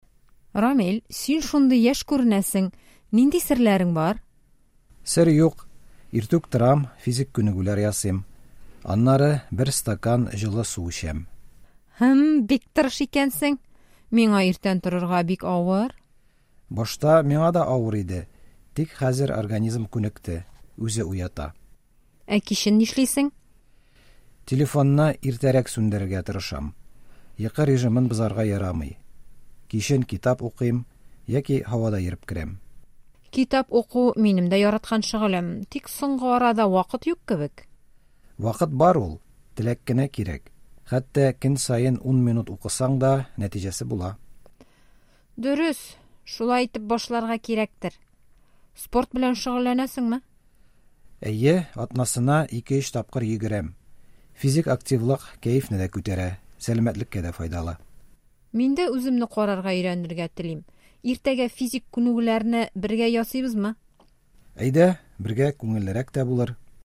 Диалог 1